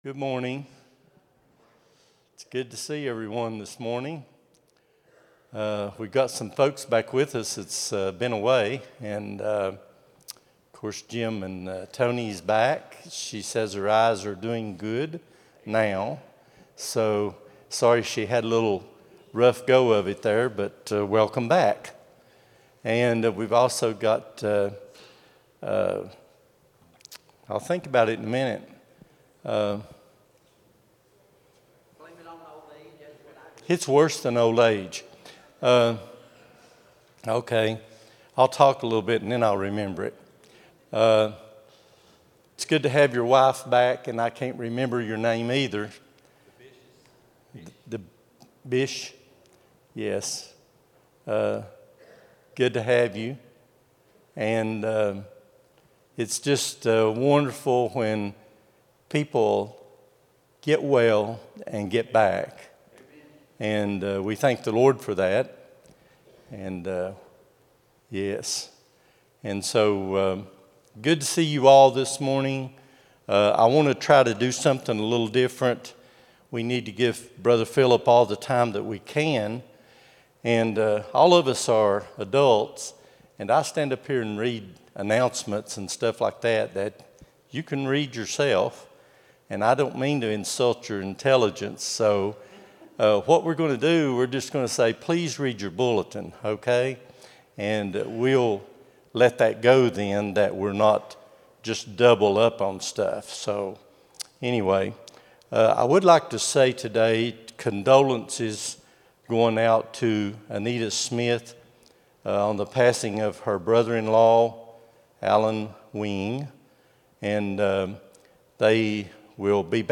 03-01-26 Sunday School | Buffalo Ridge Baptist Church